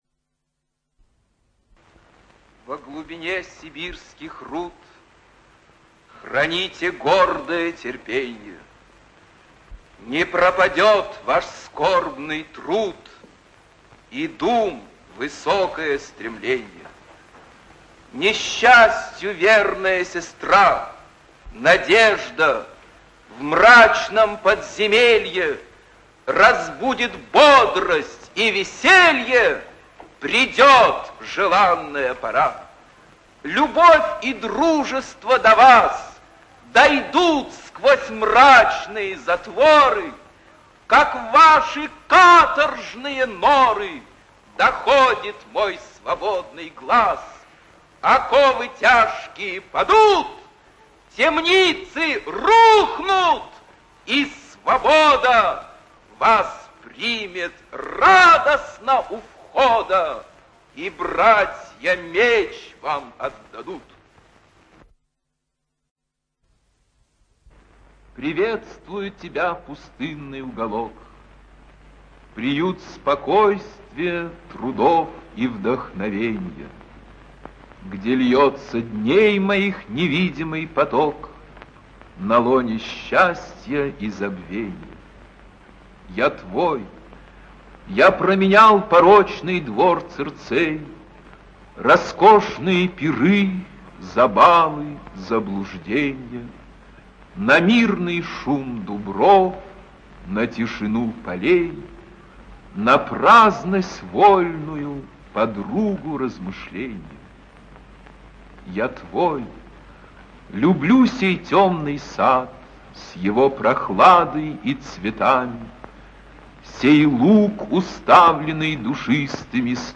ЧитаетЯхонтов В.
ЖанрПоэзия
Пушкин А - Стихотворения  (Яхонтов В.)(preview).mp3